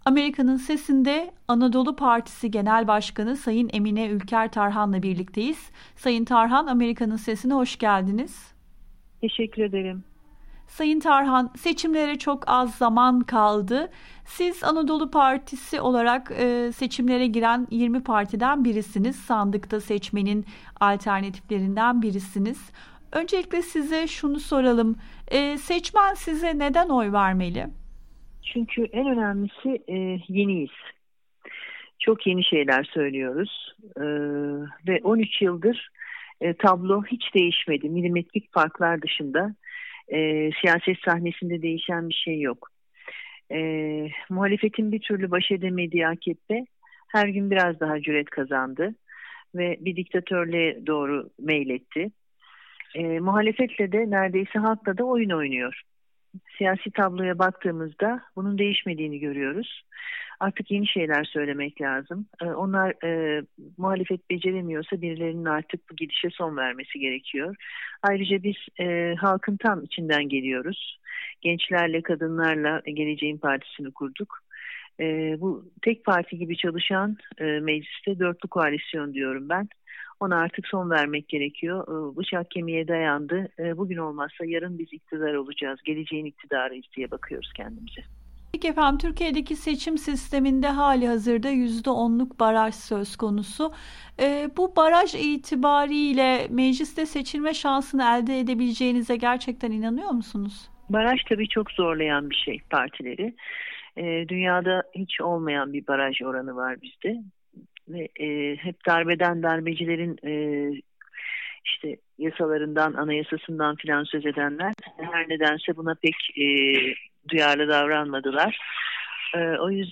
Emine Ülker Tarhan'la söyleşi